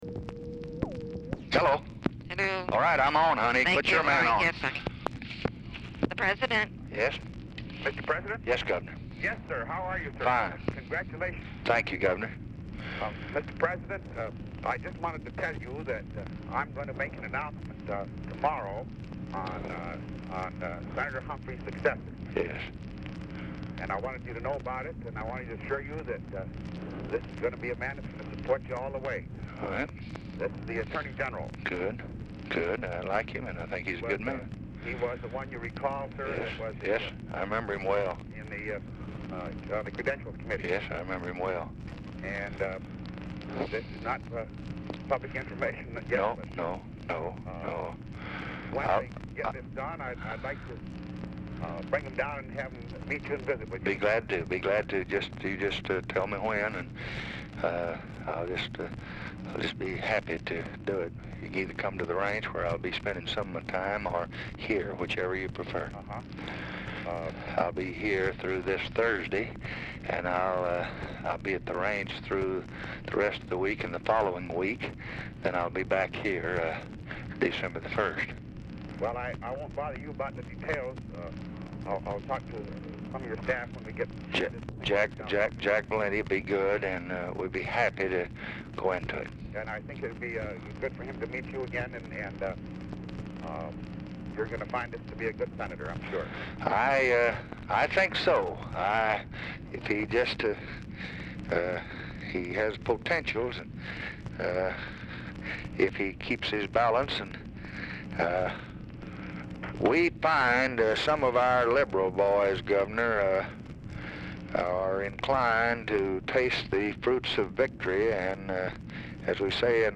Telephone conversation # 6358, sound recording, LBJ and KARL ROLVAAG, 11/16/1964, 11:05AM
Format Dictation belt
Oval Office or unknown location